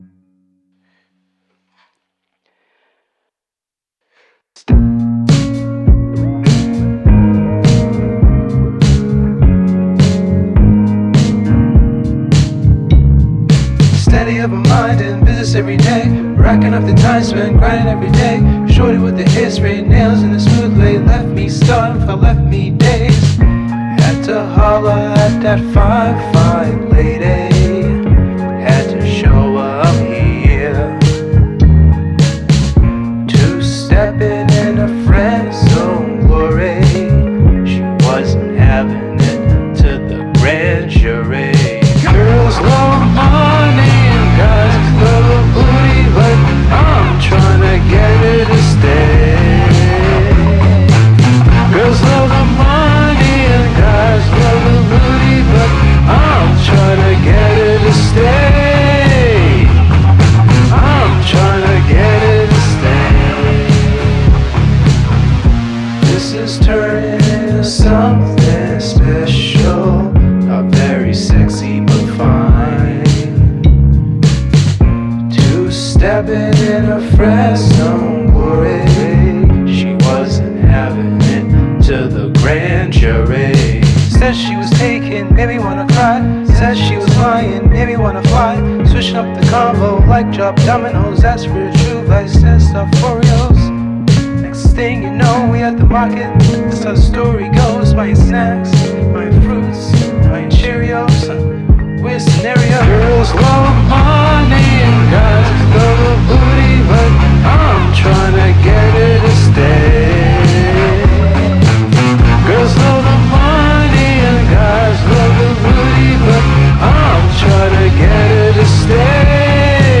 Rnb/Pop song im currently working on